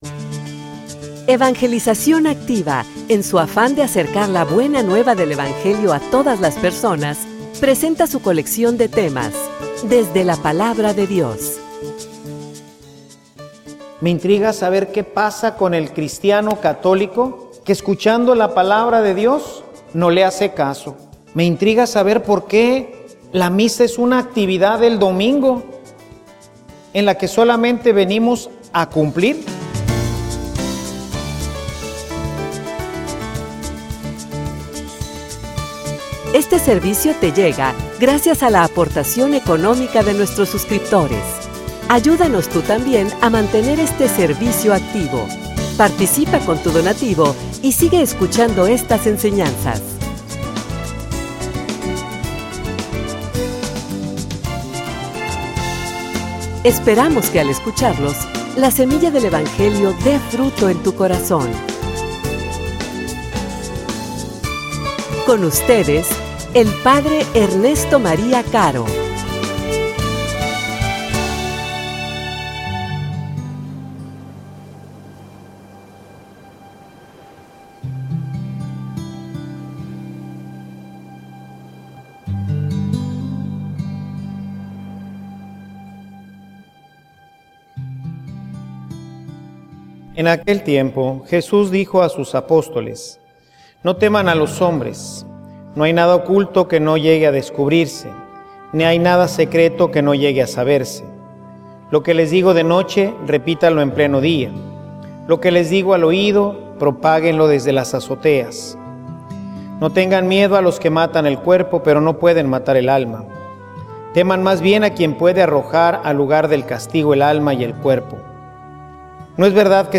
homilia_No_tengan_miedo.mp3